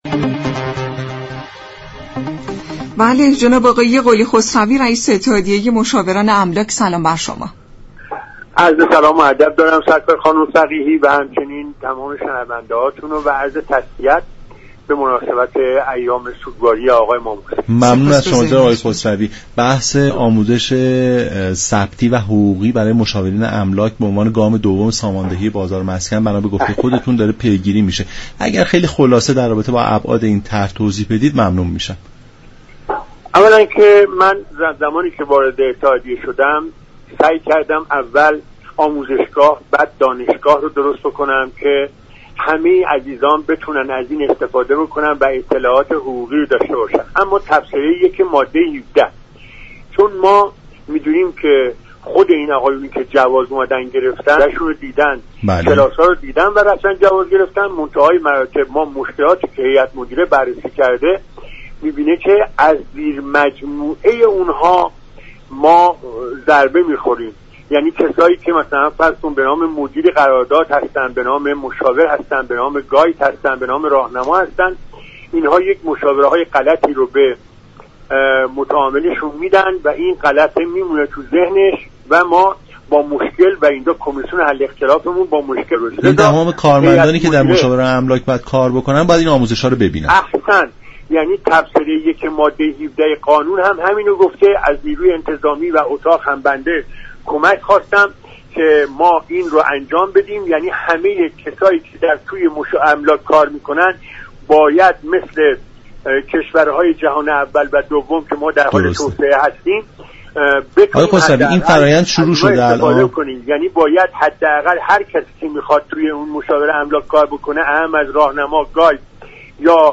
در گفت و گو با برنامه «نمودار»